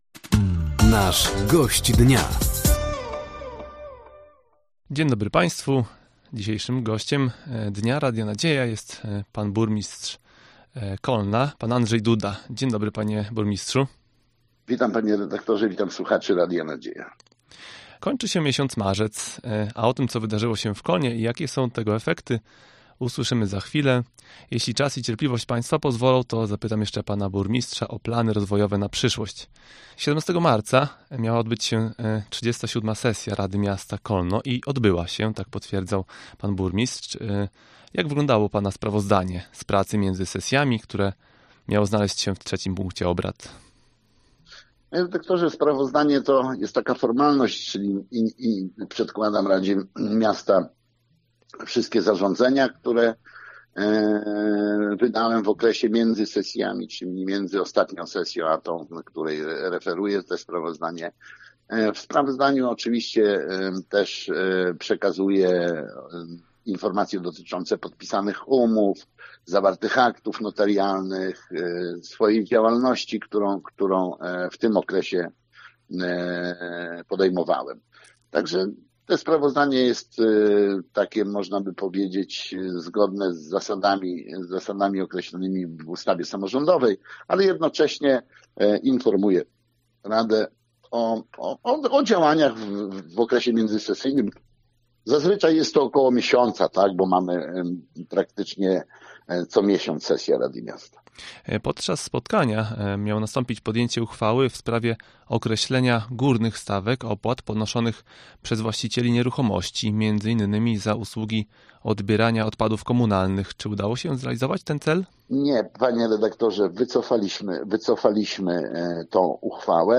Gościem Dnia Radia Nadzieja był Andrzej Duda, Burmistrz Kolna.